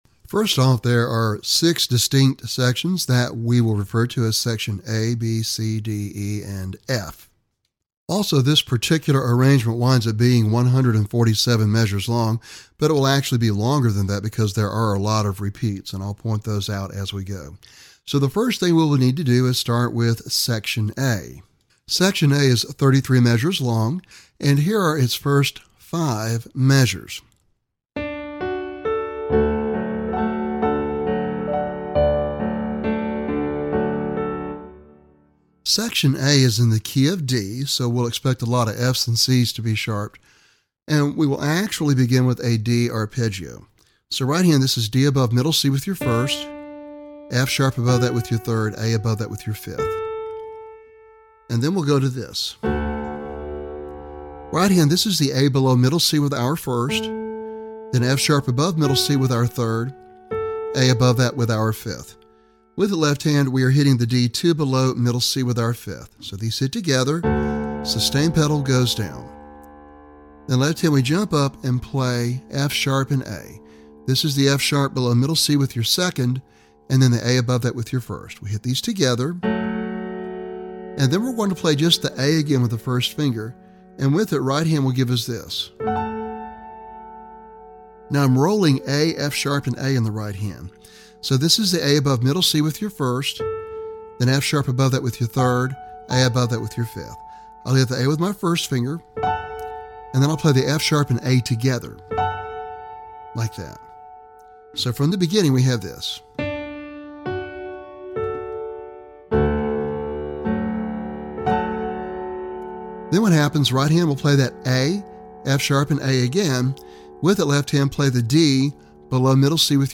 Lesson Sample
This piano arrangement